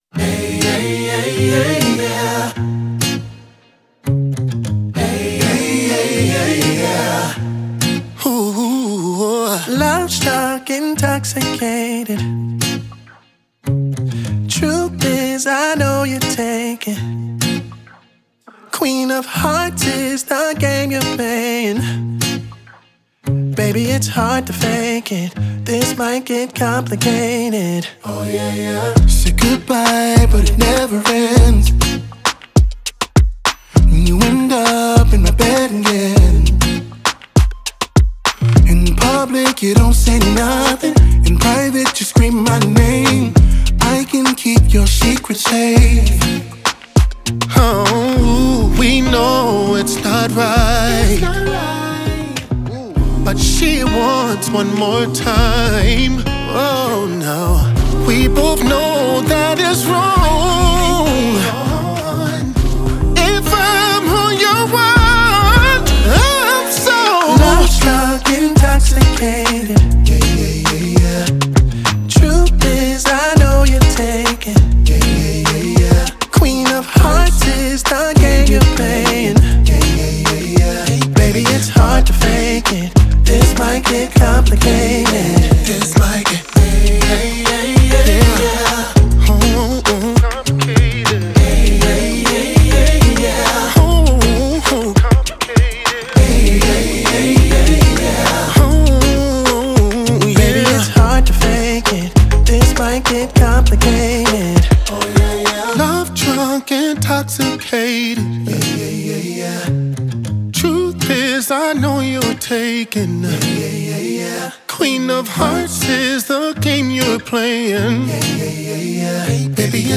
зажигательная R&B песня